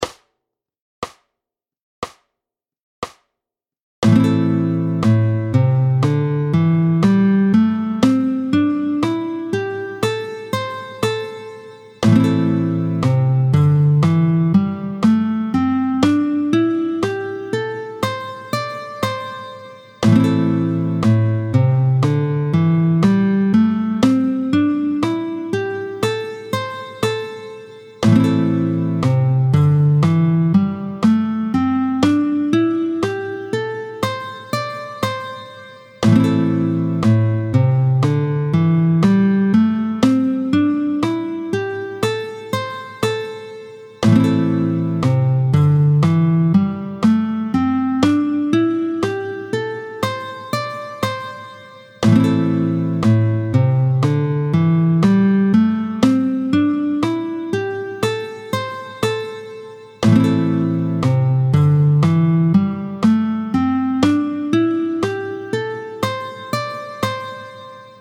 32-01 Lam Doigtés 1 et 2, tempo 60